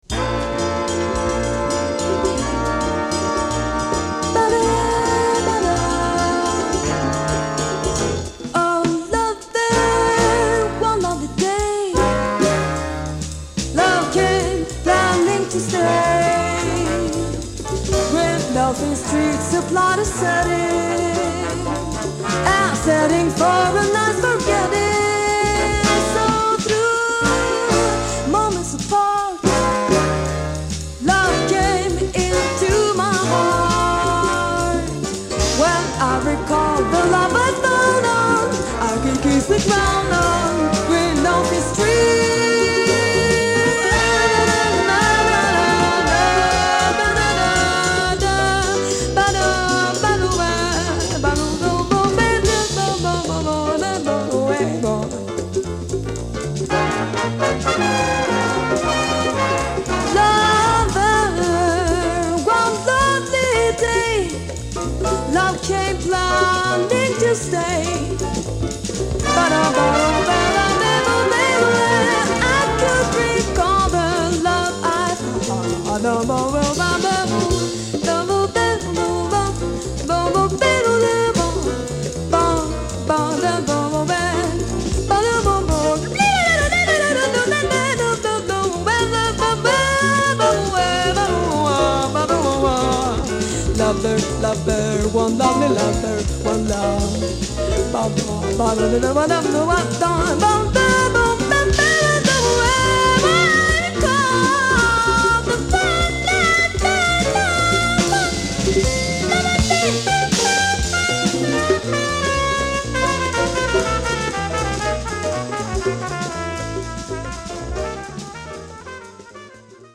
随所で伸びやかな歌声を披露している女性ヴォーカルも絶品です。